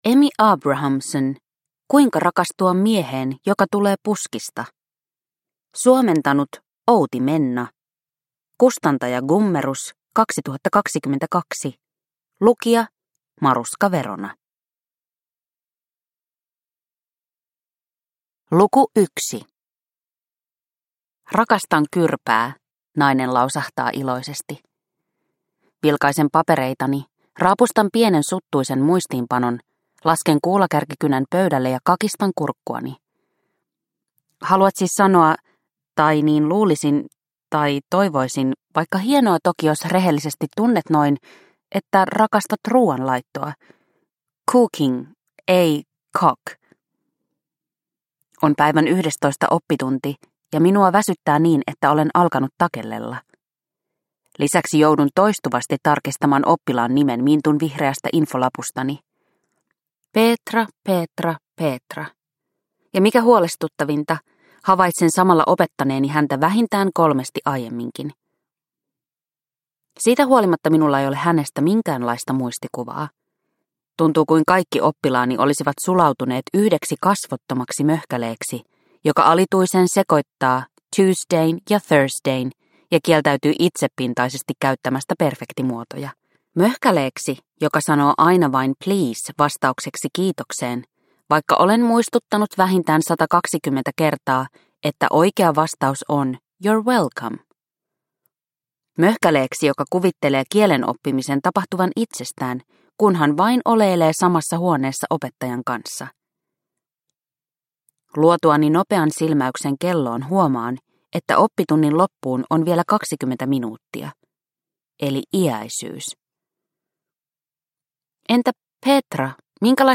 Kuinka rakastua mieheen joka tulee puskista – Ljudbok – Laddas ner